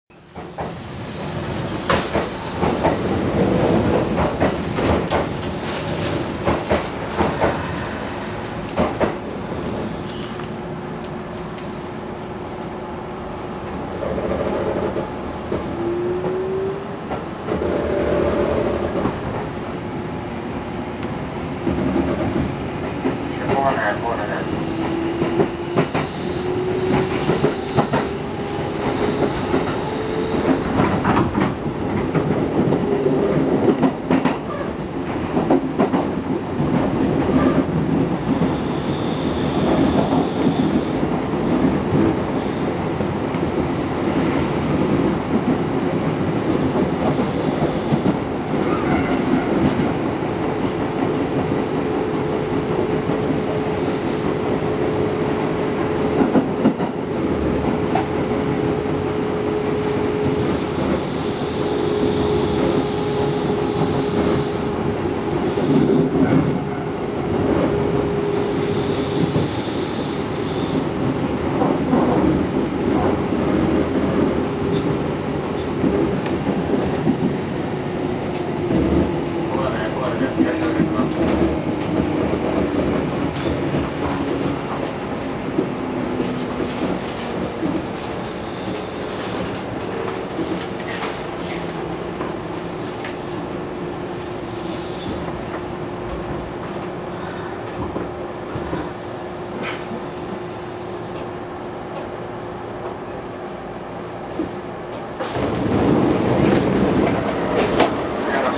・2000系走行音
【名古屋線】米野→黄金（1分48秒：851KB）…2012F